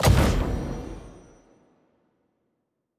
Special Click 07.wav